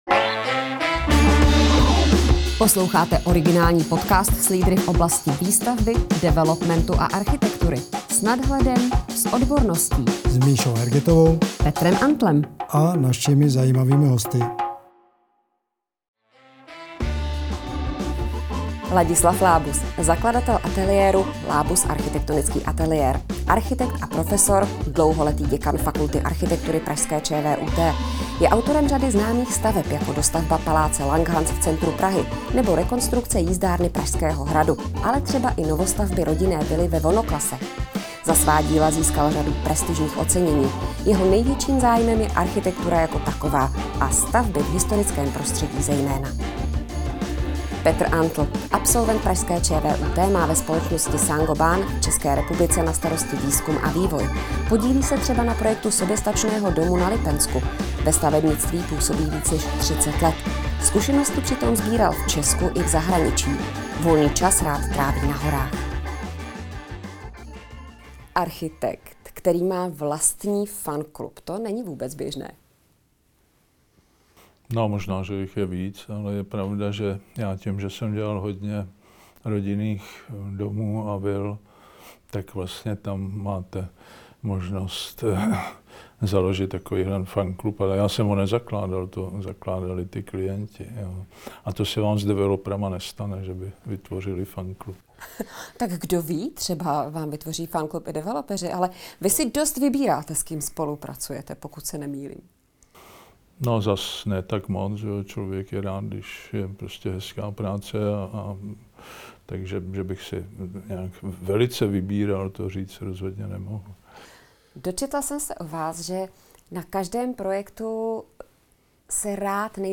Diskuze